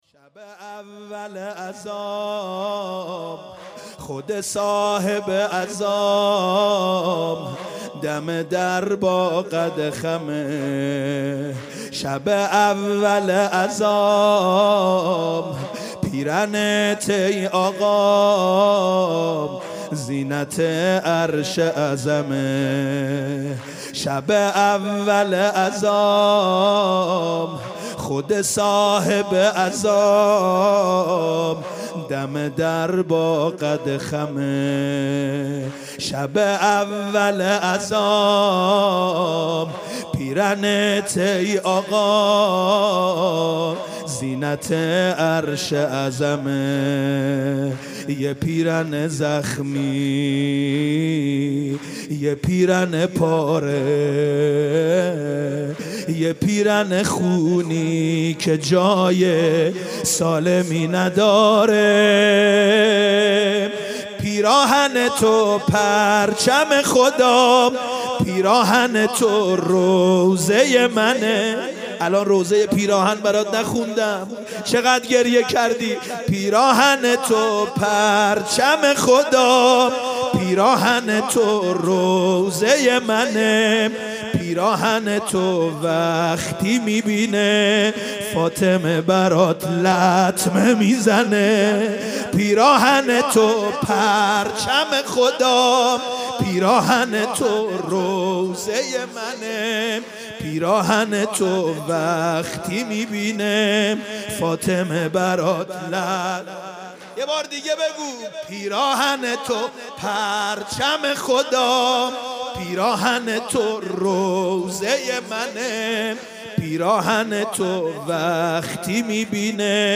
شب اول محرم سال 95/هیت رزمندگان اسلام